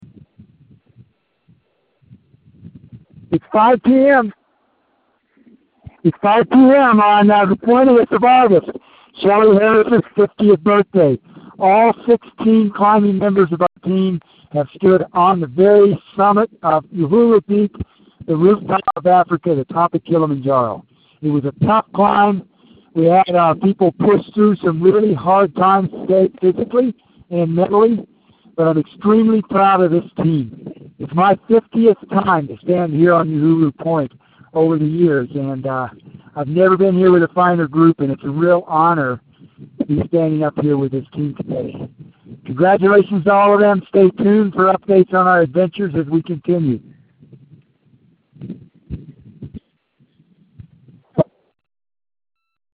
Second part: Uhuru peak, the summit of Kilimanjaro